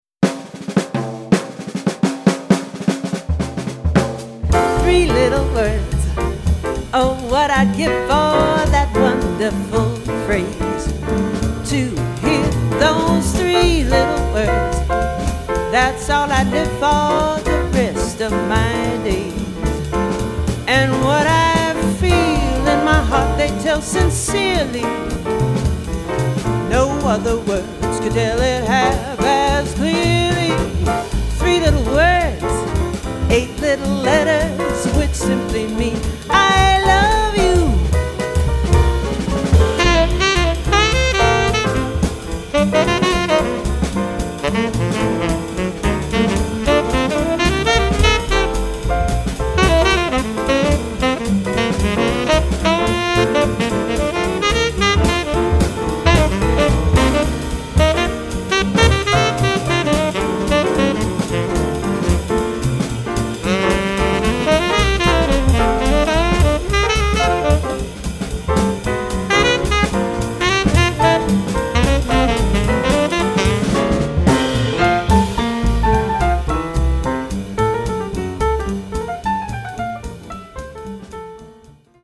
tenor sax and vocals
piano
bass
-drums